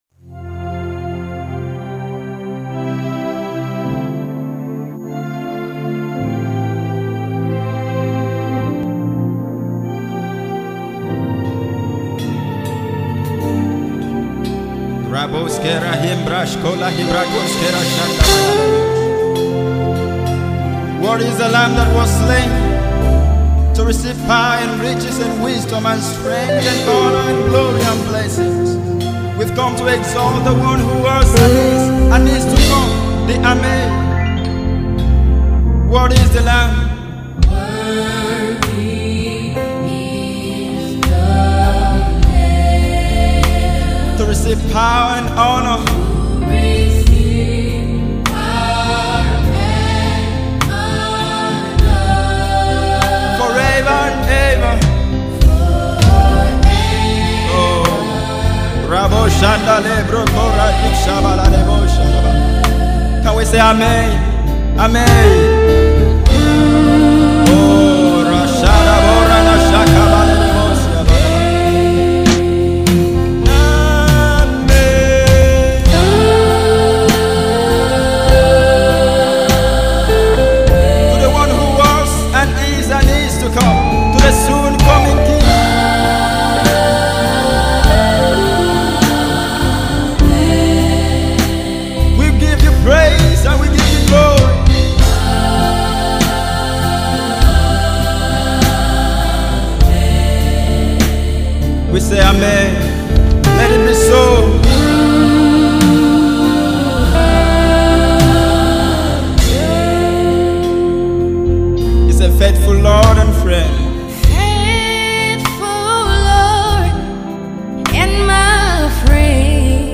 spirit-lifting song of adoration and submission